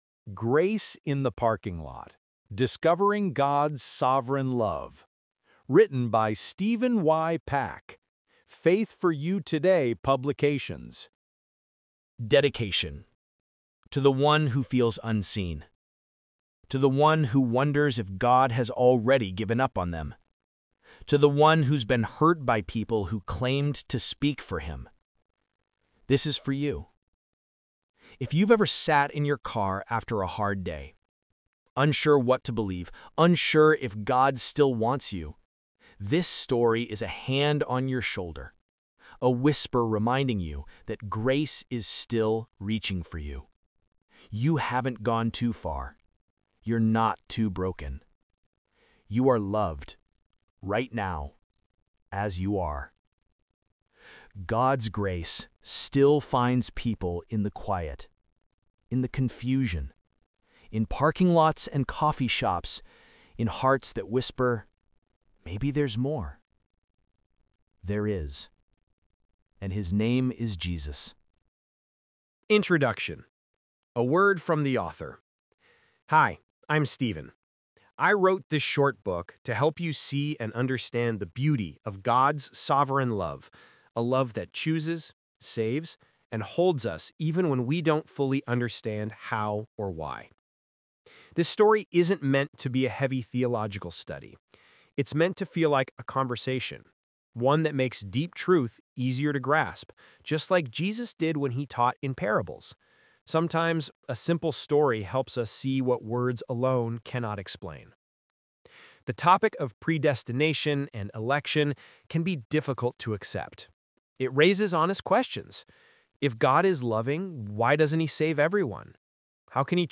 Limited Time: Grace in the Parking Lot: Discovering God's Sovereign Love, Audiobook Edition.